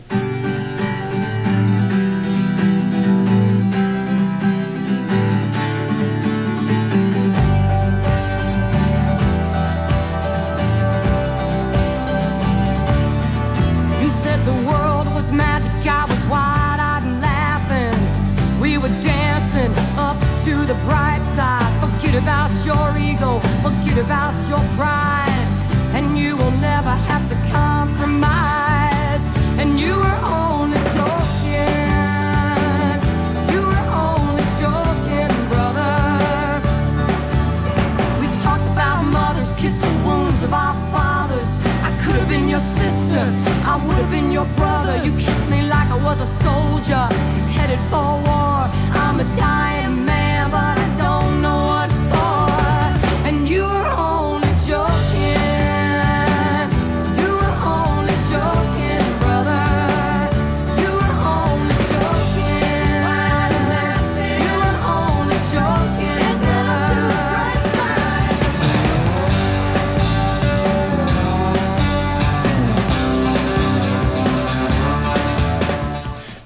lifeblood: bootlegs: 1992-xx-xx: wbfo - buffalo, new york
02. joking (1:16)